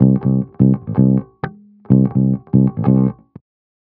13 Bass Loop C.wav